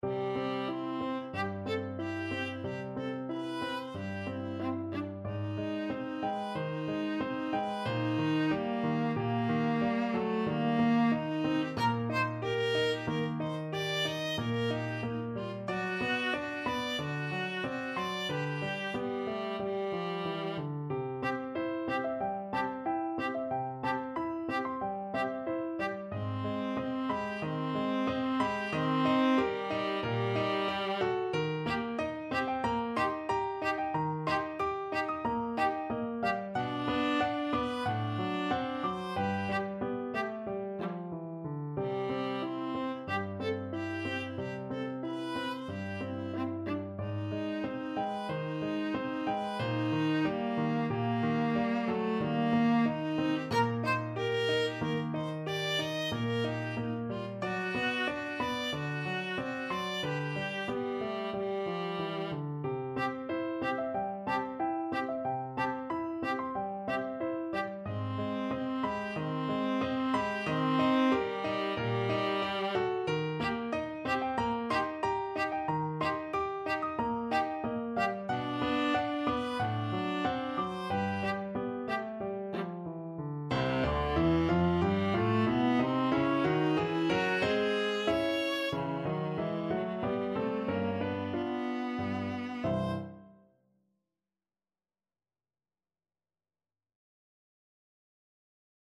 Andantino =92 (View more music marked Andantino)
Classical (View more Classical Viola Music)